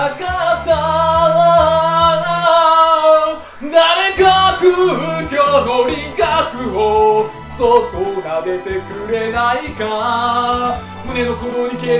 で、ひととおり操作してみて、行きつけのカラオケ屋で自分の歌を録音してみた。
普段から、エコーに頼らない歌唱を目指しており、カラオケ屋のマイクは使わず生声なので、これもそうだ。
DR-05Xの方は、入力レベルの調整に失敗して割れてしまったが、音質に月とスッポンほどの差がある。